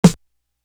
Professional Snare.wav